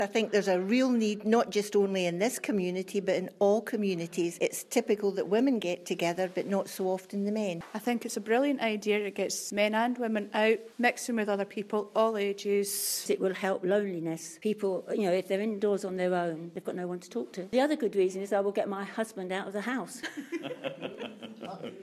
LISTEN: Ladies give their views on Peebles Men's Shed